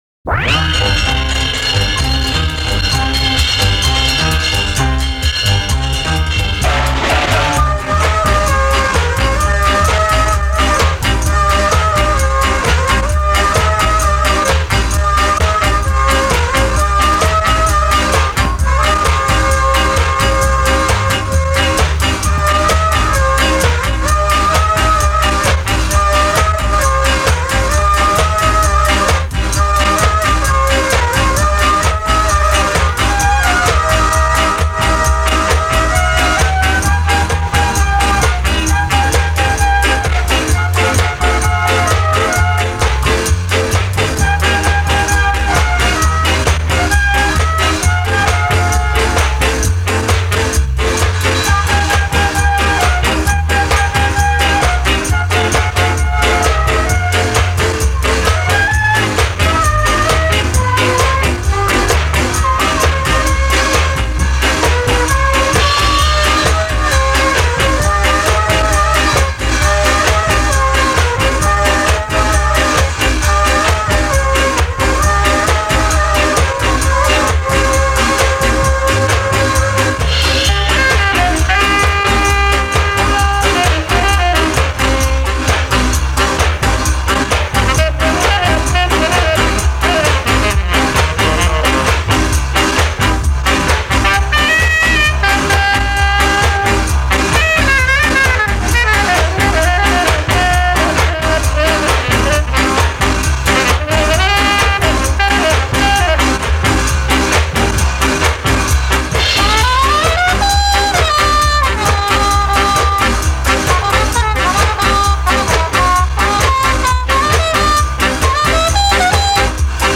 Vieux ska jamaicain